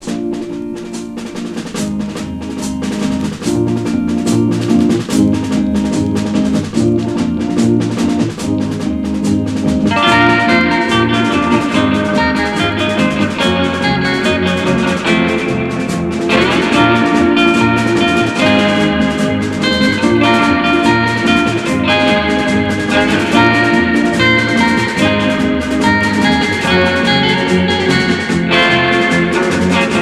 Rock instrumental Deuxième EP retour à l'accueil